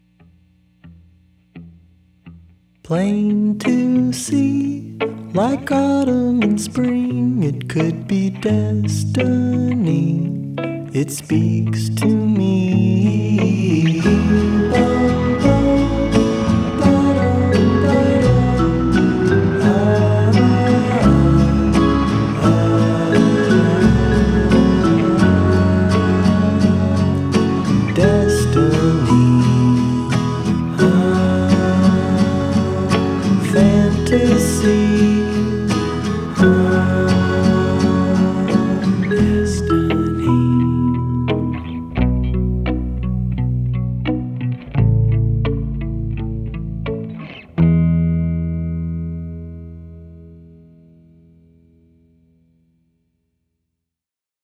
electric guitars, bass guitars, keyboards, noise, snare